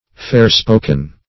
Search Result for " fair-spoken" : The Collaborative International Dictionary of English v.0.48: Fair-spoken \Fair"-spo`ken\, a. Using fair speech, or uttered with fairness; bland; civil; courteous; plausible.